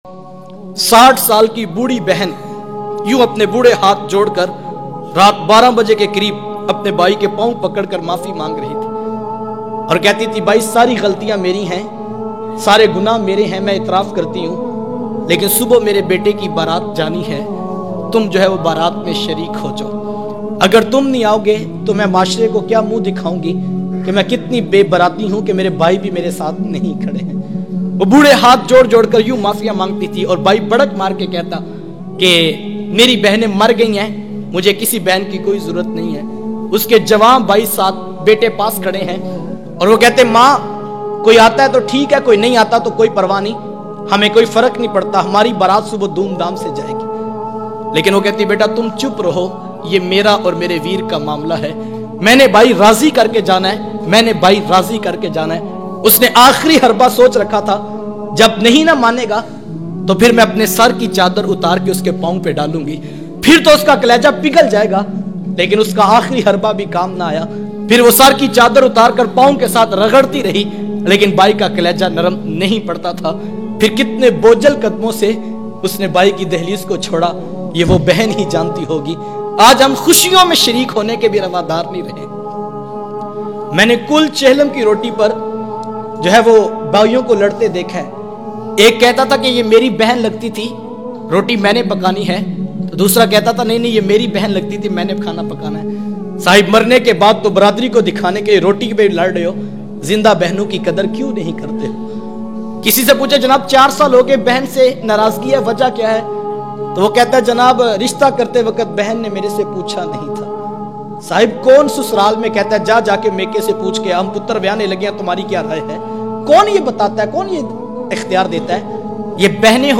60 sal ki bhori behan ka waqia islamicdb bayan mp3
6o sal ki bhori behan ka waqia islamicdb bayan AM.mp3